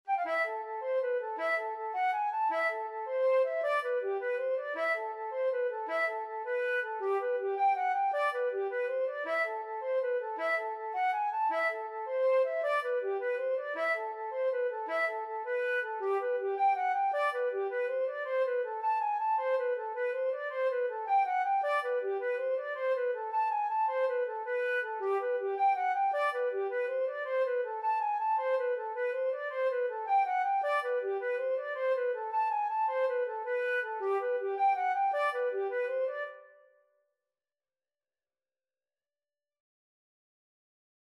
6/8 (View more 6/8 Music)
A minor (Sounding Pitch) (View more A minor Music for Flute )
Flute  (View more Easy Flute Music)
Traditional (View more Traditional Flute Music)